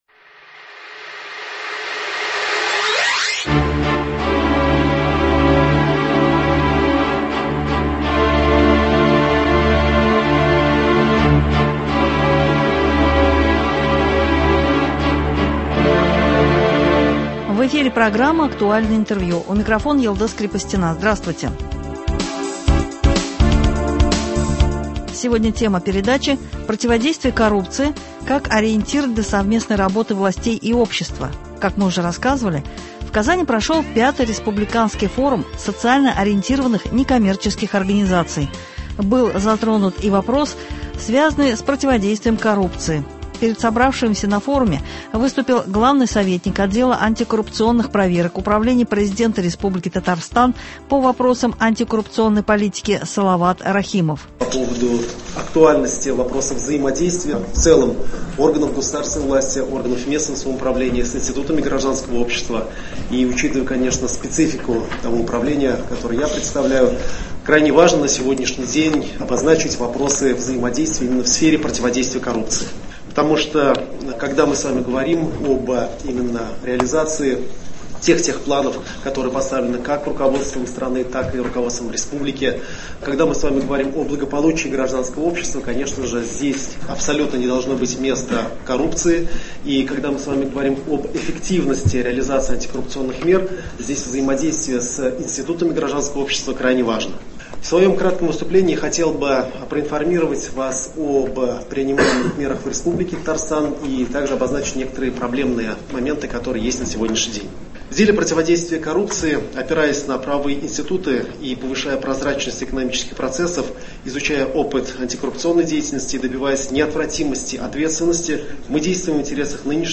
Актуальное интервью (31.08.22) | Вести Татарстан